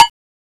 Index of /m8-backup/M8/Samples/Fairlight CMI/IIX/PERCUSN1
COWBELL2.WAV